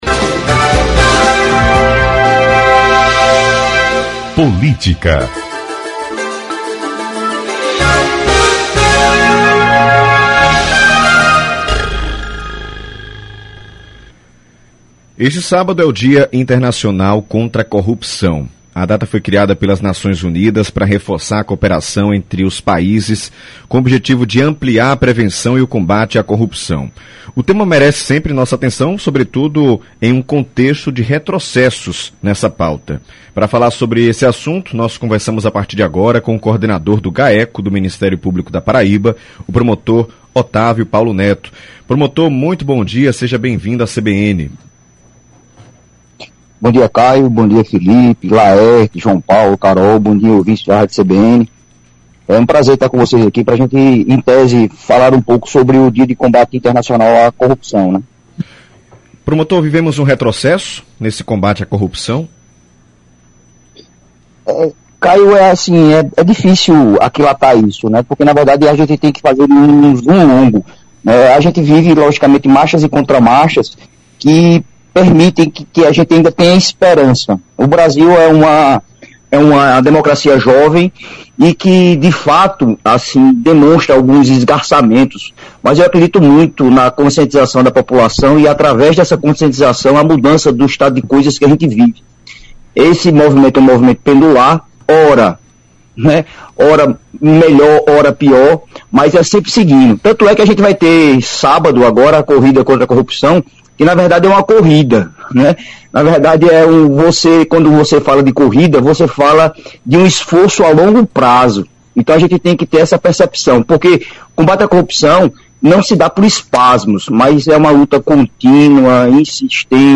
Ouça a entrevista de Octávio Paulo Neto à Rádio CBN na íntegra: